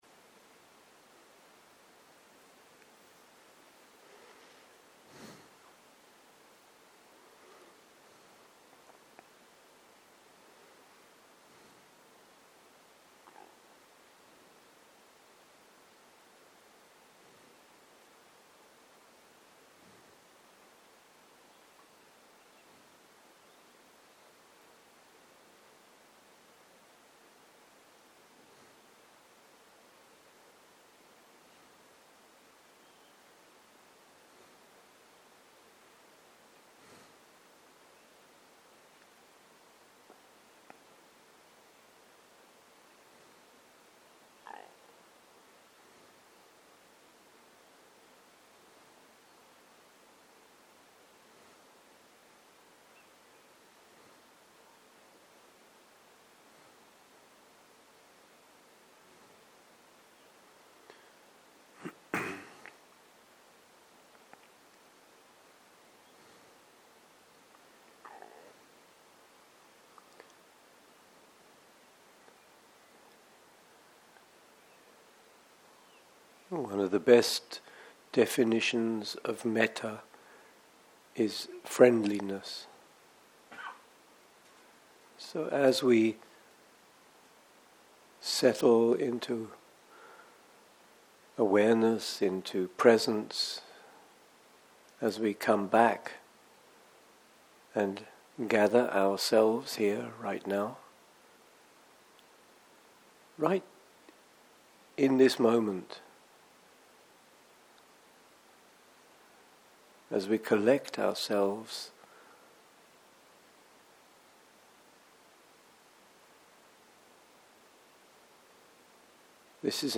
מדיטציה מונחית - Friendliness - צהריים
סוג ההקלטה: מדיטציה מונחית